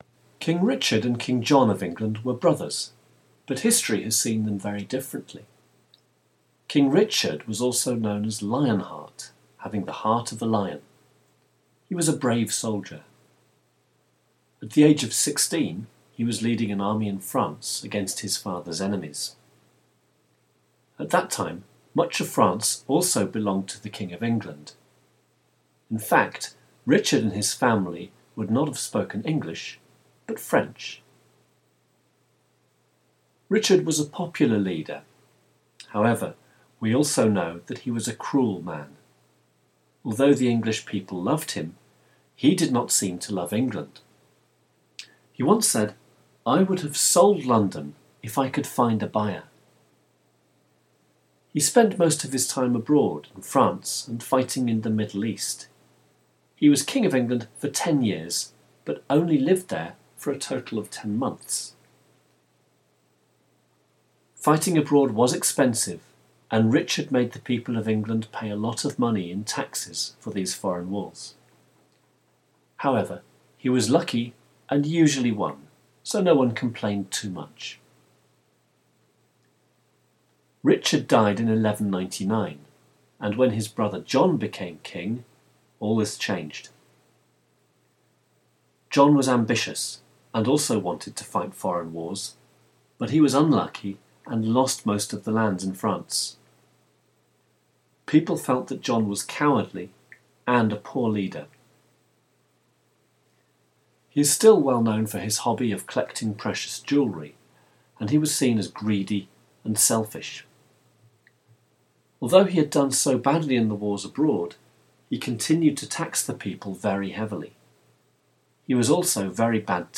King Richard and King John lecture extract.mp3